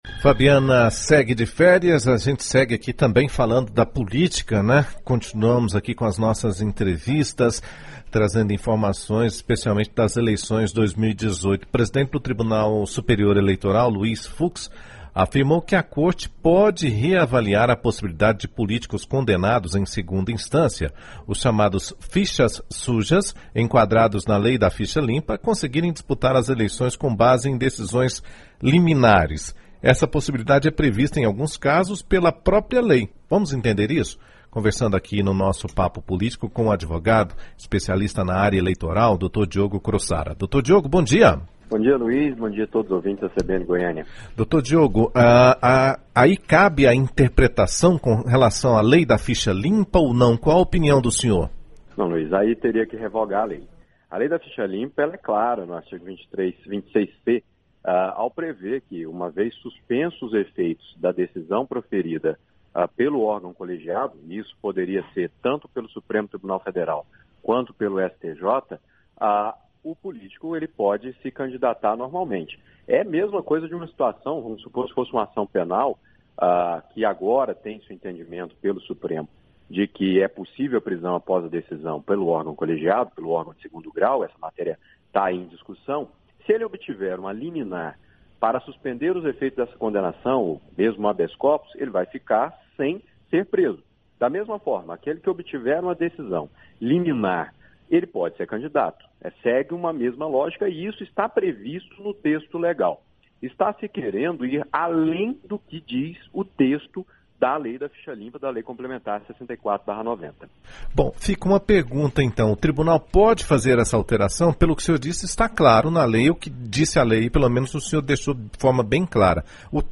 Confira o áudio da entrevista completa: /wp-content/uploads/2018/02/CBN_PAPO_09_02_18.mp3 Compartilhar: Facebook 18+ LinkedIn WhatsApp E-mail